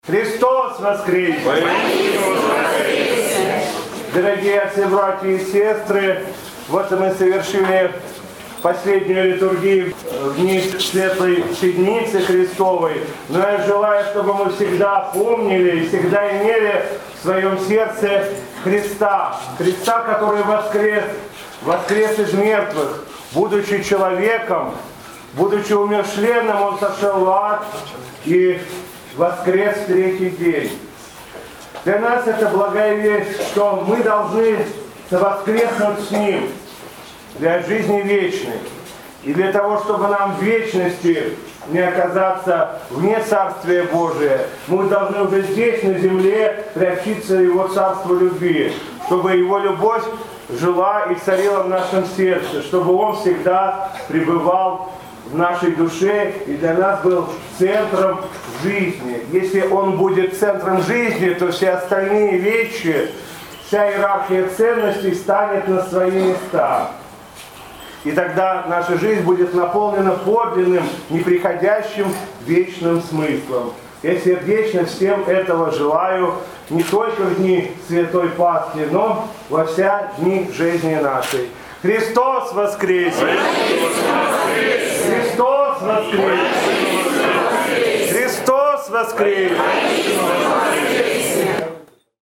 Затем владыка Игнатий поздравил присутствующих со светлым праздником Пасхи и обратился к ним с архипастырским словом.